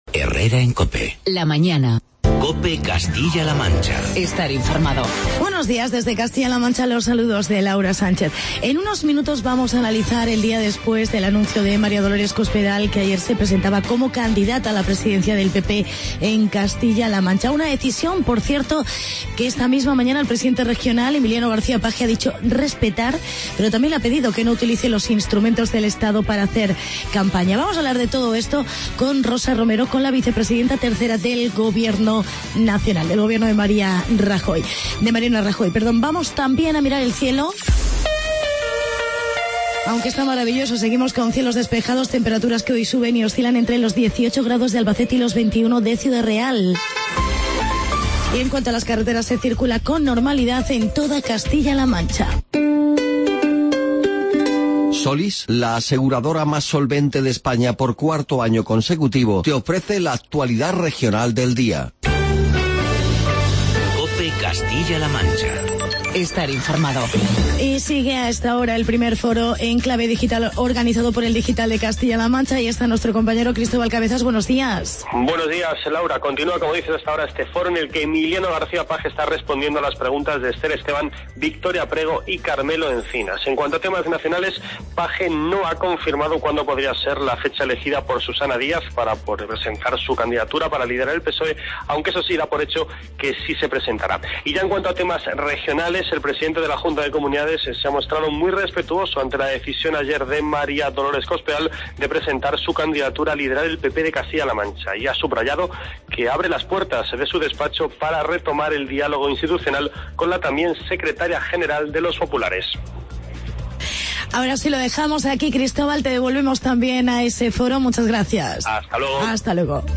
Actualidad y entrevista con Rosa Romero, vicepresidenta 3ª del Gobierno y Vicesecretaria de Estrategia y Acción Polítia del PP de CLM.